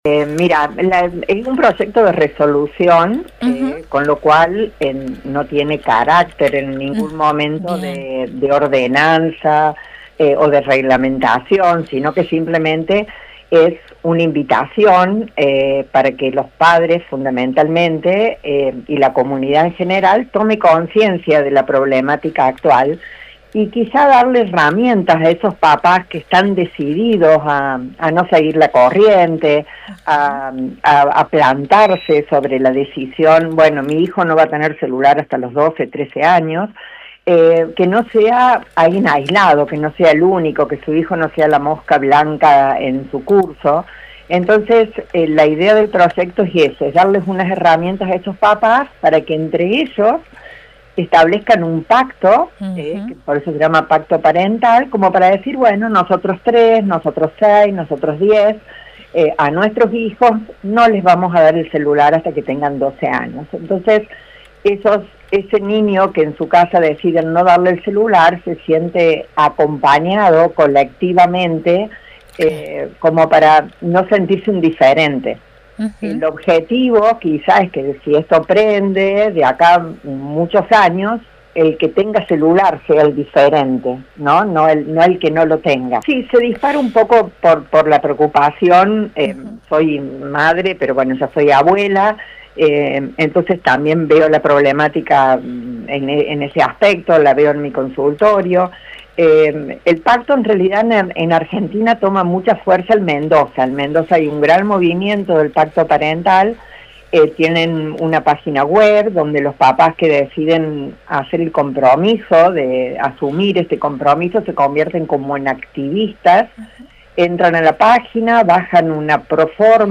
En diálogo con Universidad, dijo que se trata de un proyecto de resolución que invita a padres a tomar conciencia sobre la situación.